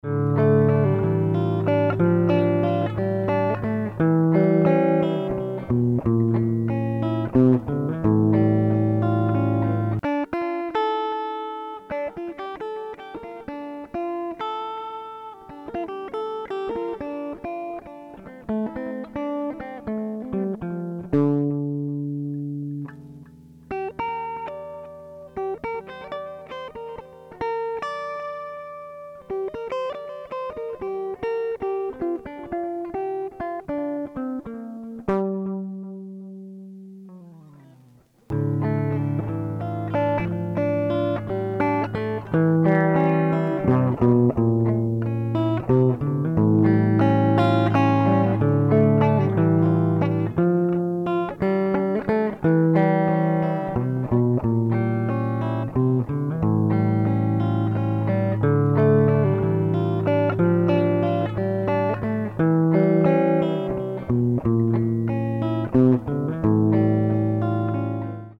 Наиграл на гитаре,  там  помнит длинное вступление